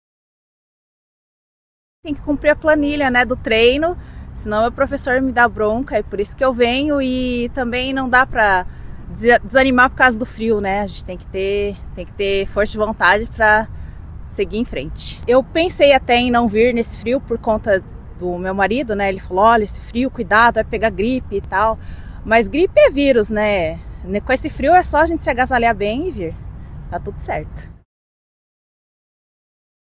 A reportagem da CBN Curitiba esteve no Jardim Botânico e encontrou muita gente que escolheu começar o dia ao ar livre.